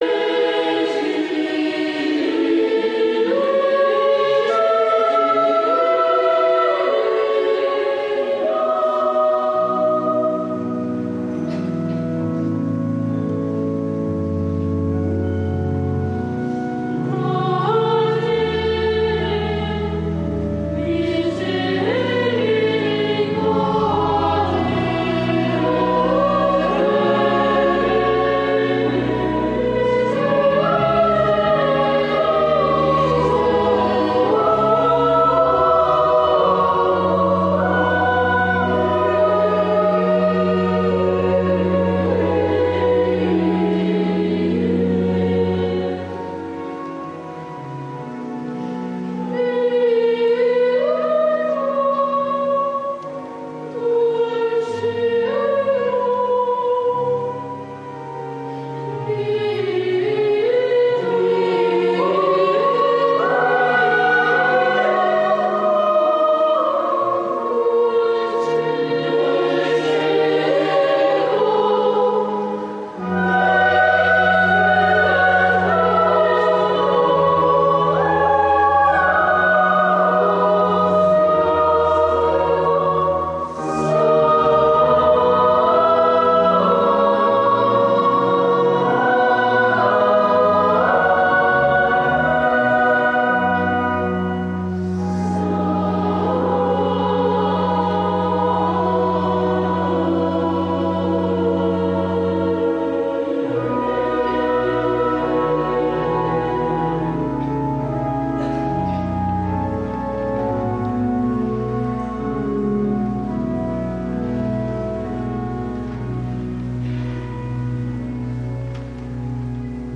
amb l'Escolania de Montserrat
L’escolania de Montserrat canta la Salve i el Virolai als peus de la Moreneta, com un moment de pregària al migdia, en el qual hi participen molts fidels, pelegrins i turistes que poden arribar omplir a vessar la basílica.